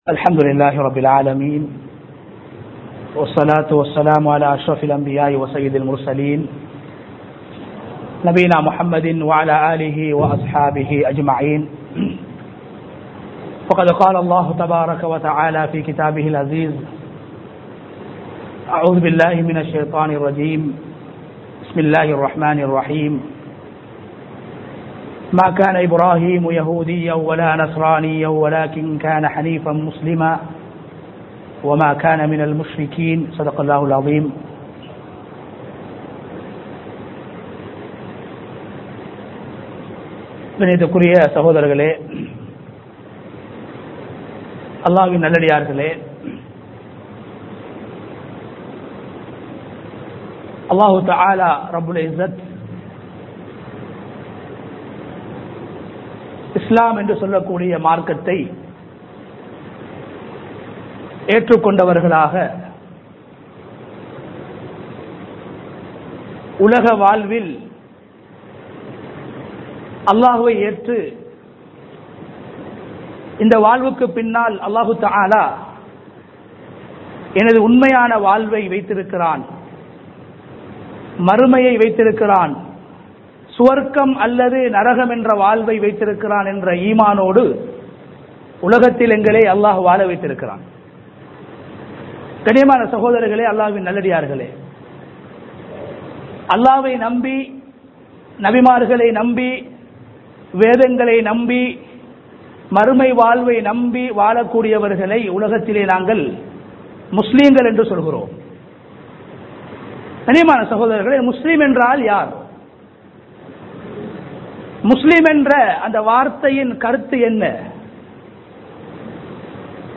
உழ்ஹிய்யாவும் அடிமைத்தனமும் | Audio Bayans | All Ceylon Muslim Youth Community | Addalaichenai
Colombo 02, Tholabe Garden Jumuah Masjith 2023-06-02 Tamil Download